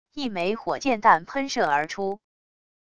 一枚火箭弹喷射而出wav音频